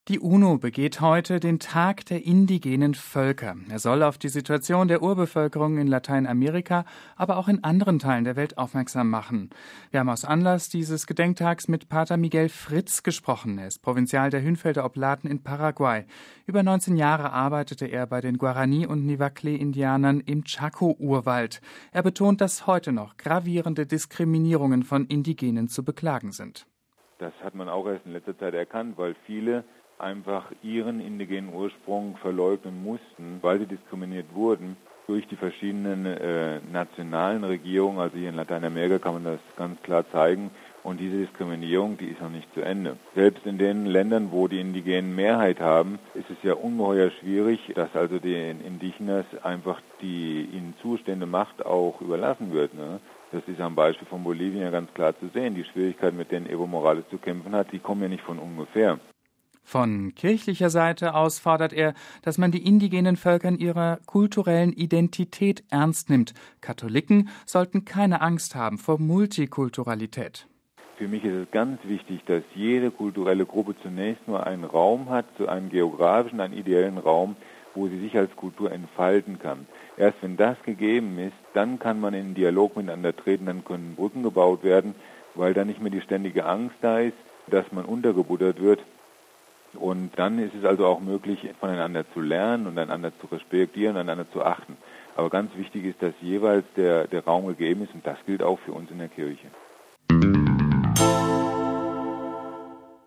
MP3 Die UNO begeht an diesem Samstag den Tag der Indigenen Völker.